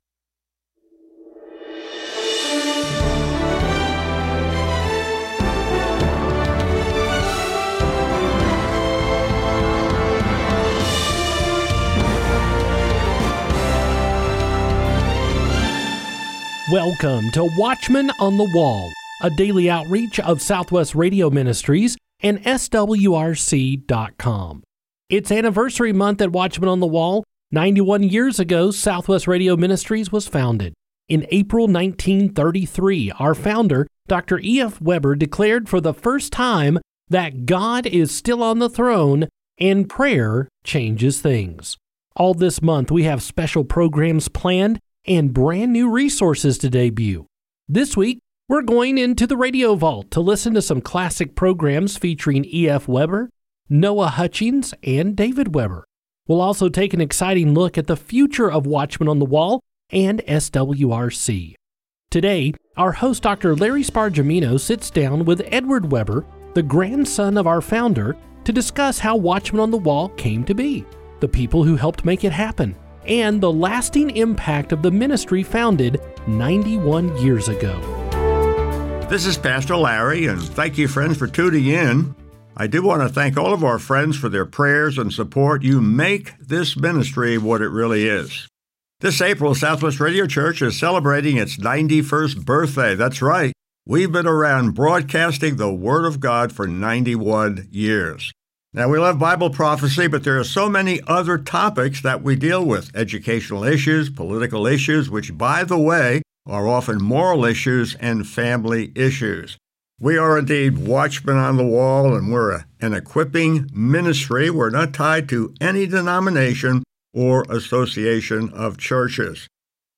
On this special 91st anniversary broadcast